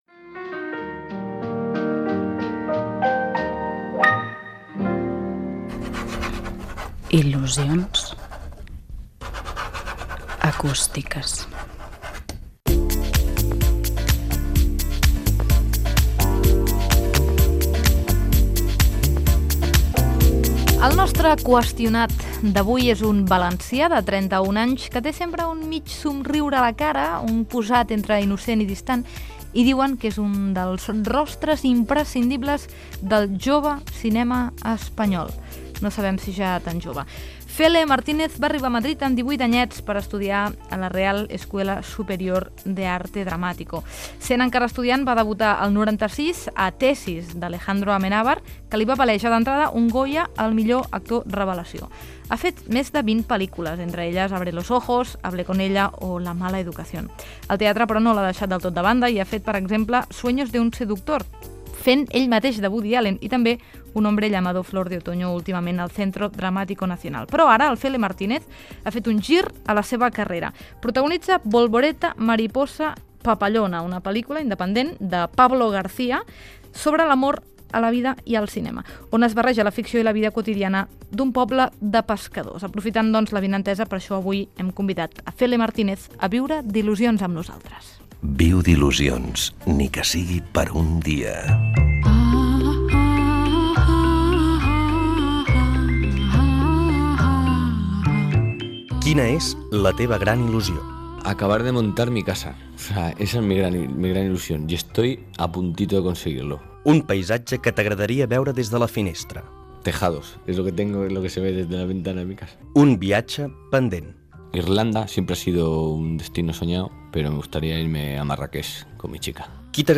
Indicatiu del programa, espai "Qüestionat", amb un fragment d'una entrevista a l'actor Fele Martínez